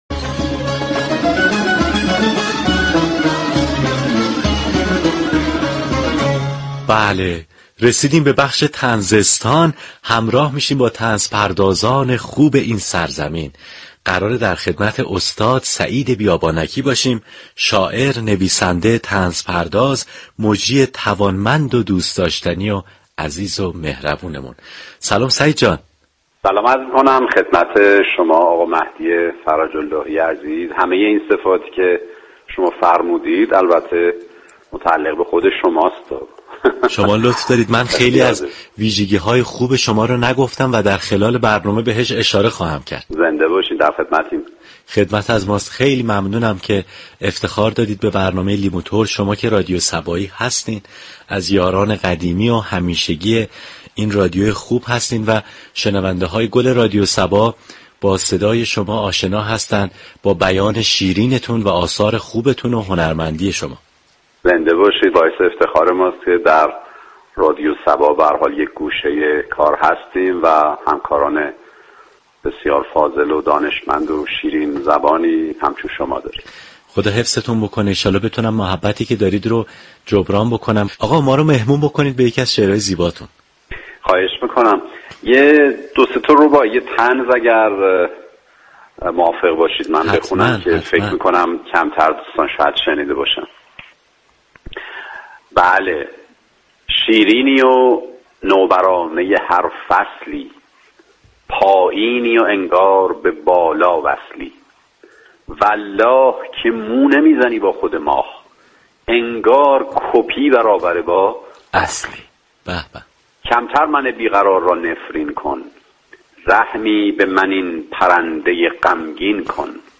شنونده گفتگوی برنامه لیموترش با سعید بیابانكی، طنزپرداز باشید.